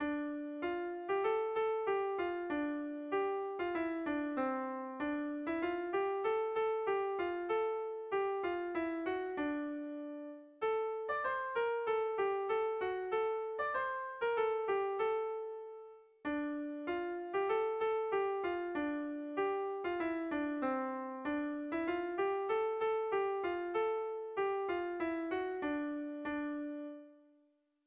Tragikoa
ABDAB